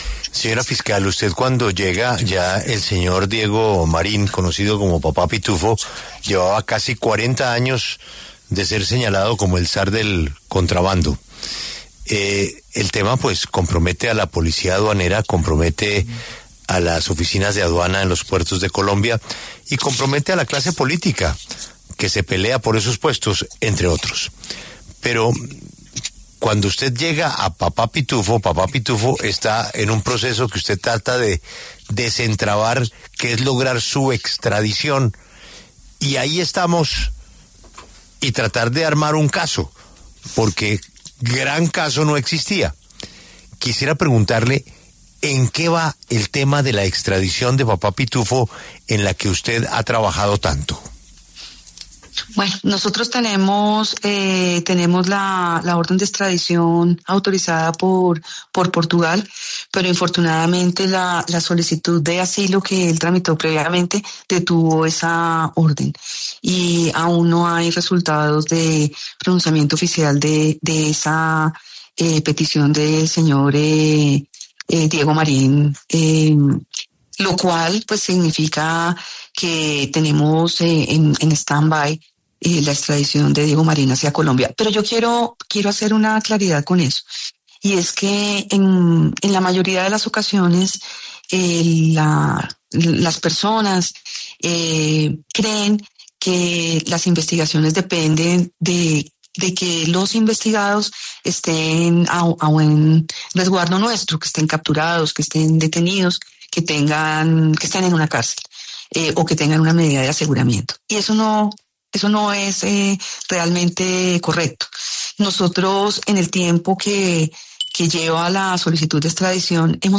La fiscal general de la Nación, Luz Adriana Camargo, habló en La W y reveló en qué va todo el proceso con alias ‘Papá Pitufo’.